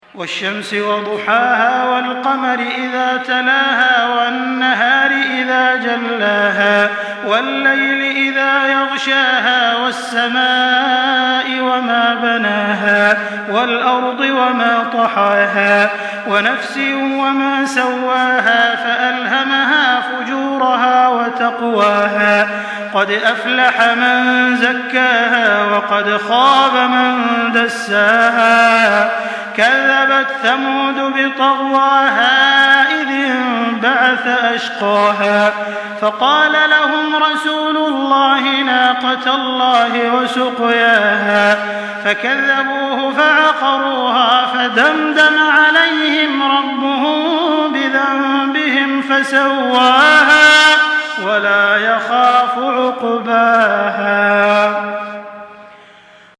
Surah Şems MP3 by Makkah Taraweeh 1424 in Hafs An Asim narration.
Murattal Hafs An Asim